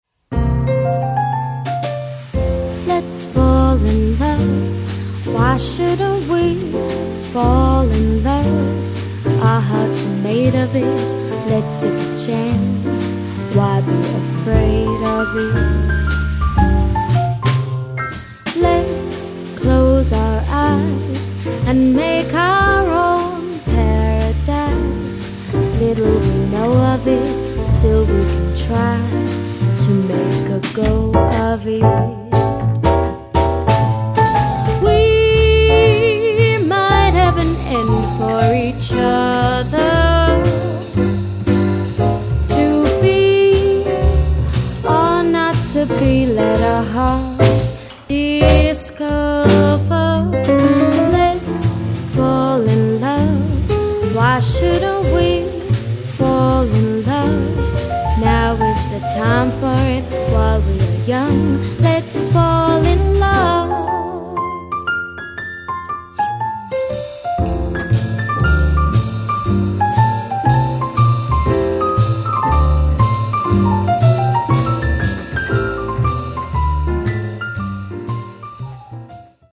a sophisticated set of swinging jazz classics
• Sophisticated vocal-led jazz band